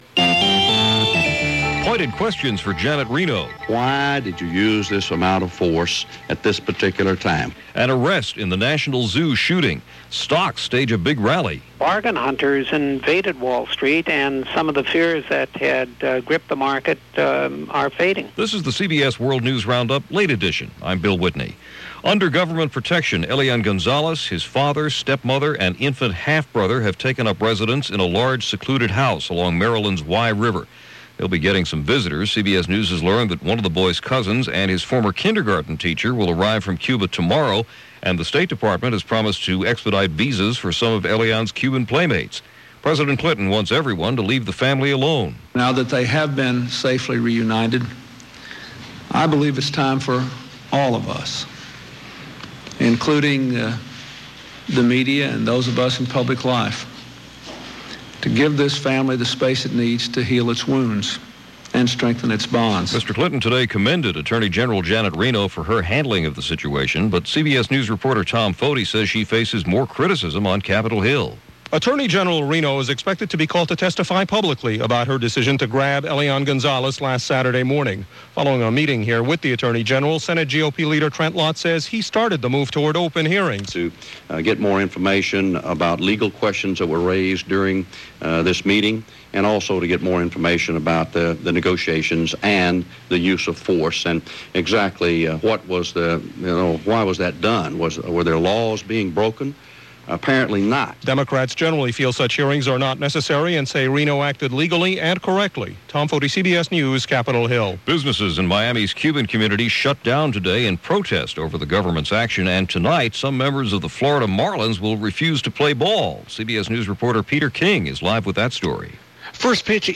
And that’s just a small sample of what went on, this rather busy and roller-coaster April 25, 2000 as reported by The CBS World News Roundup: Late Edition.